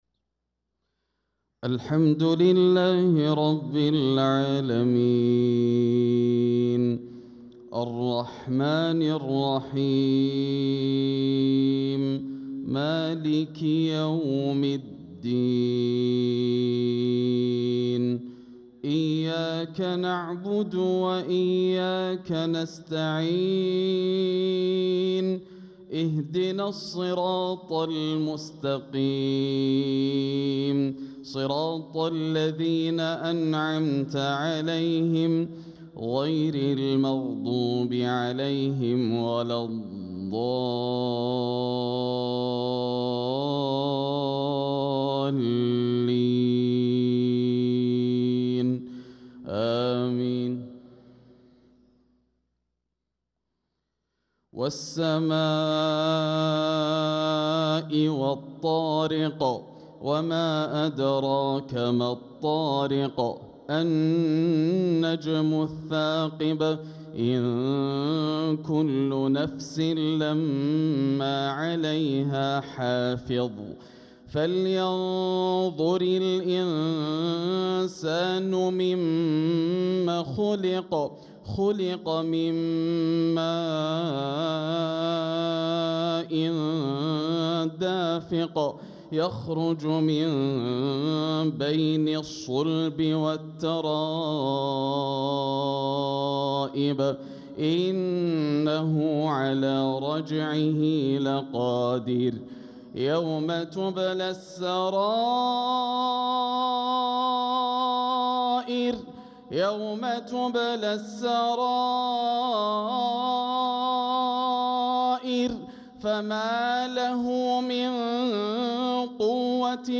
صلاة المغرب للقارئ ياسر الدوسري 21 ربيع الآخر 1446 هـ
تِلَاوَات الْحَرَمَيْن .